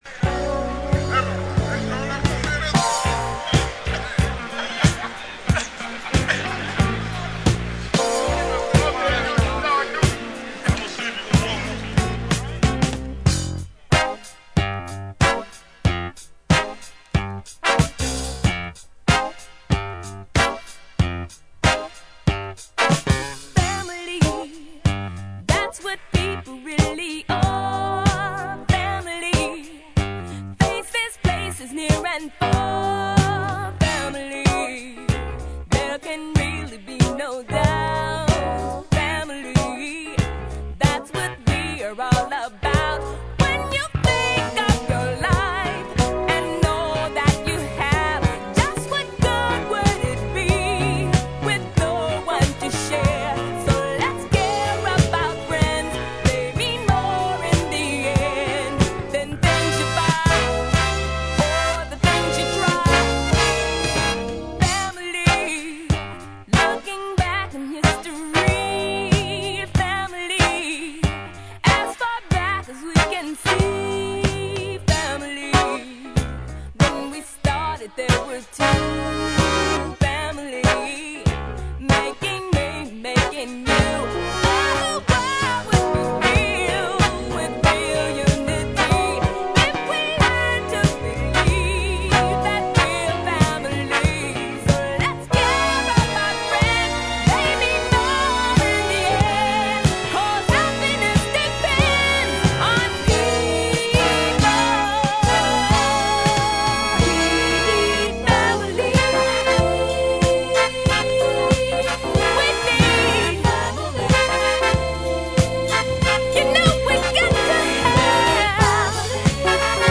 ジャンル(スタイル) SOUL / JAZZ